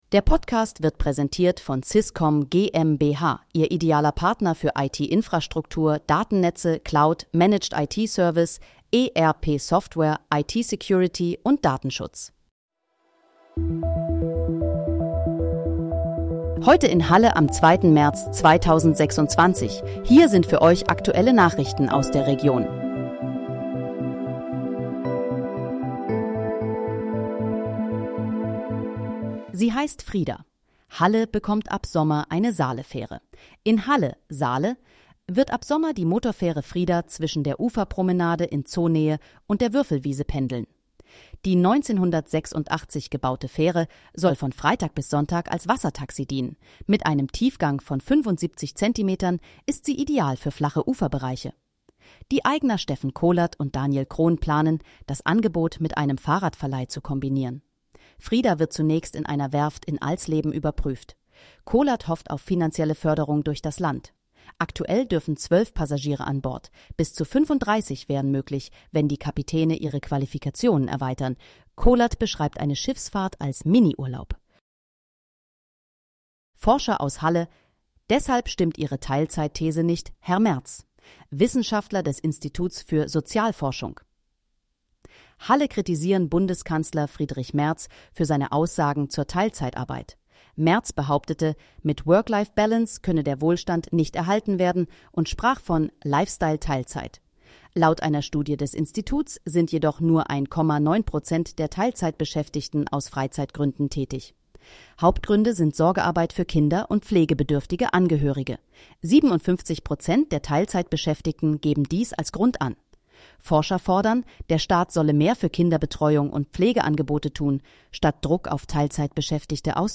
Heute in, Halle: Aktuelle Nachrichten vom 02.03.2026, erstellt mit KI-Unterstützung